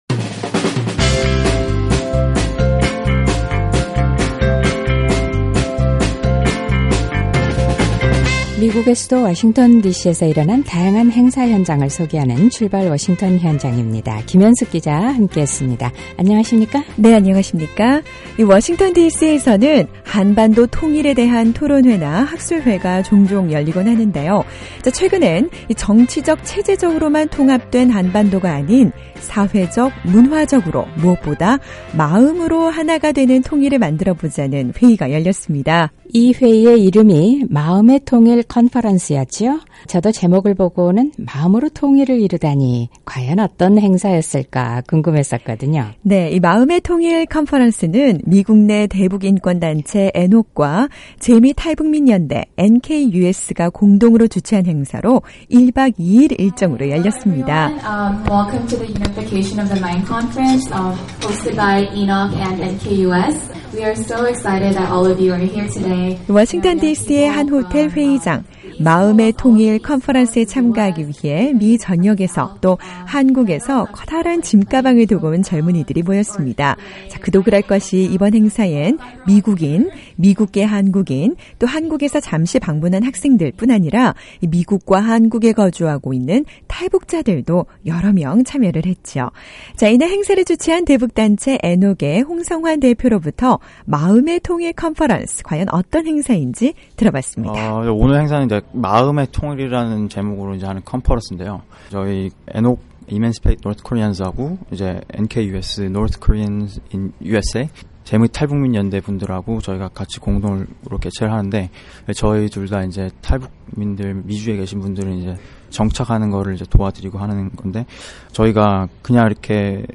미국인, 미국계 한국인, 또 북한 출신 젊은이들이 통일에 대한 생각과 마음을 모았던 컨퍼런스 현장으로 출발해봅니다.